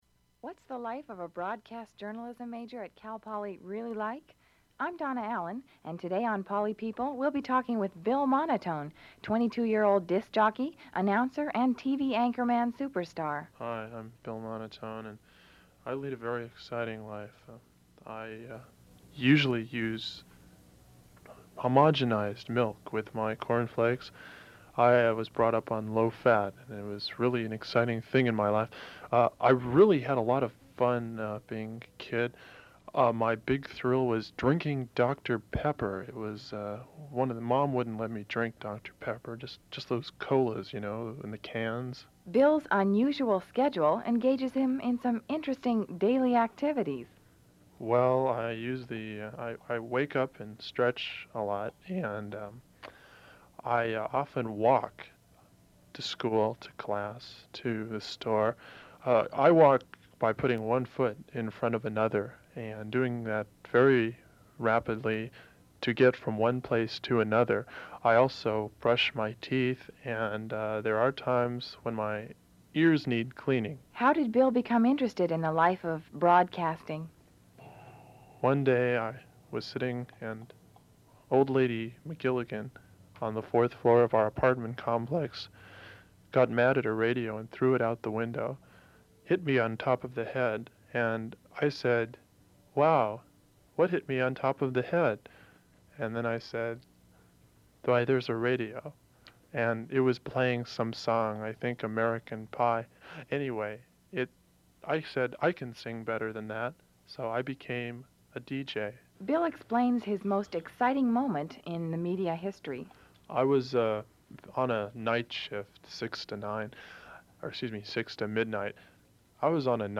Music Break
KCPR radio staff discussion
Open reel audiotape